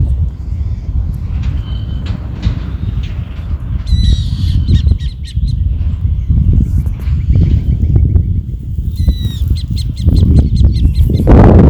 Burrowing Owl (Athene cunicularia)
Province / Department: Entre Ríos
Detailed location: Colonia Ayuí
Condition: Wild
Certainty: Observed, Recorded vocal